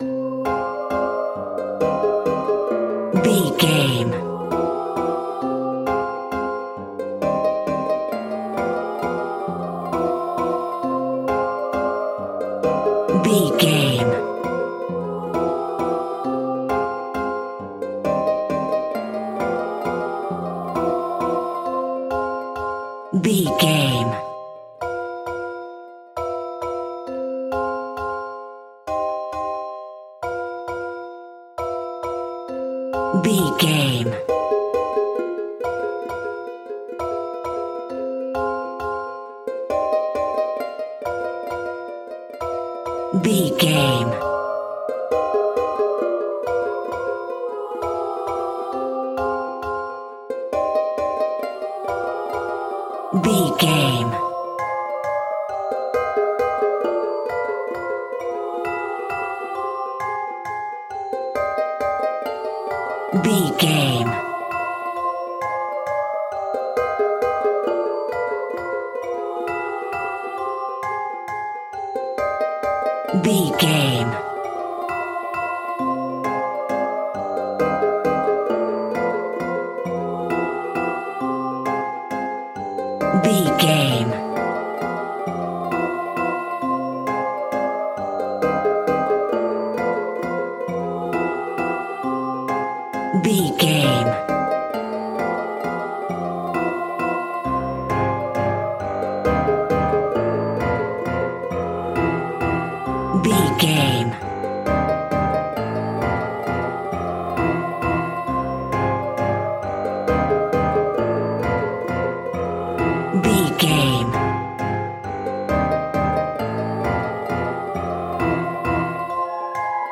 Diminished
ominous
dark
suspense
eerie
percussion
harp
strings
piano
electric piano
clown music
synth
pads
electronic